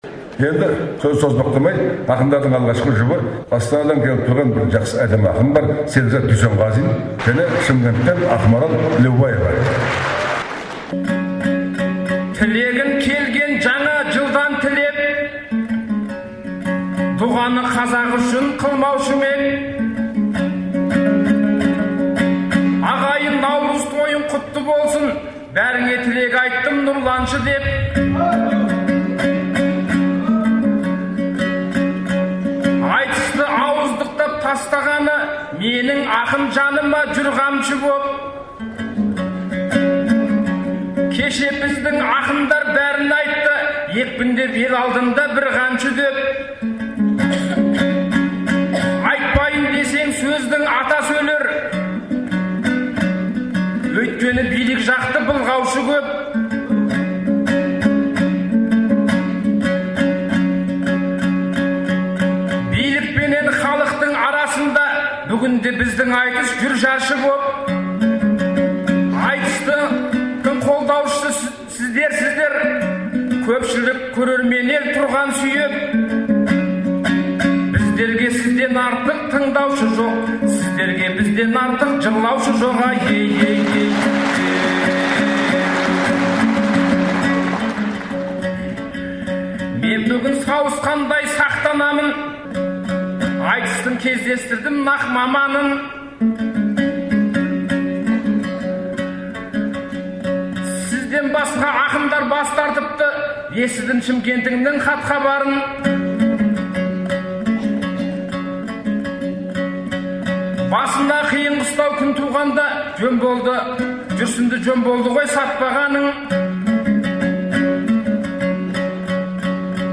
айтысы